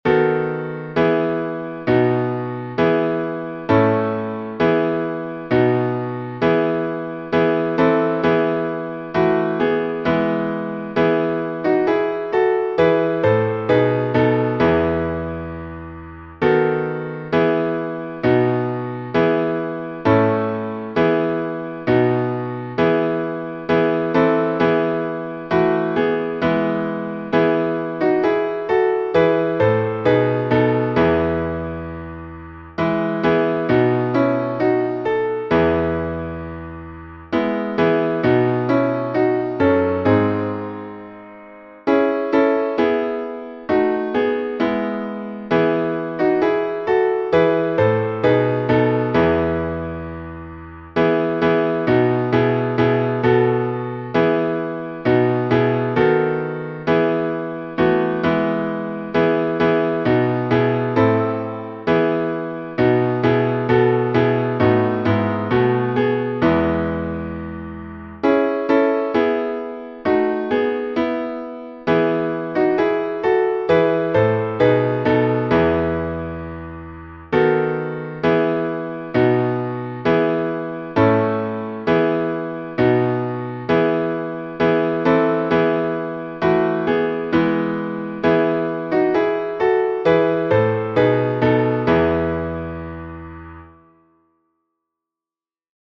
srpski_karlovacki_napev.mp3